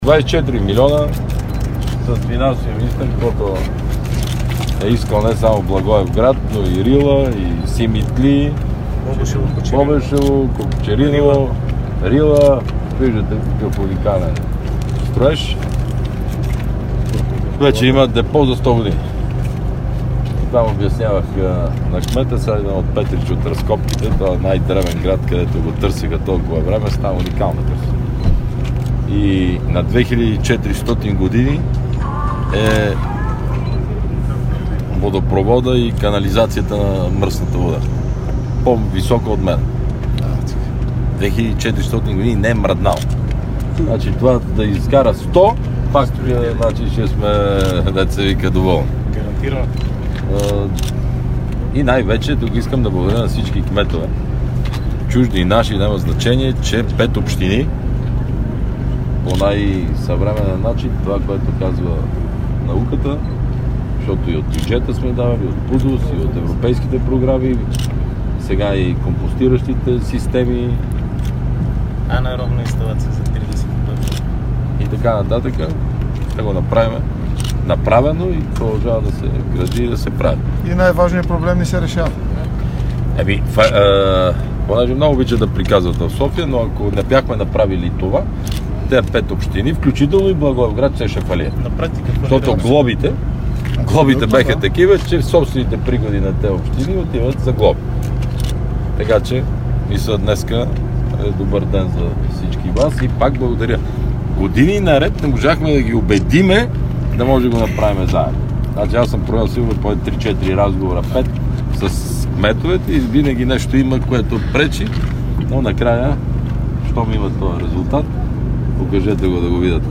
Радио К2 директно от мястото на събитието
14.20 - Брифинг на премиера Бойко Борисов и Владислав Горанов, министър на финансите от Благоевград - директно от мястото на събитието - (Благоевград) интернет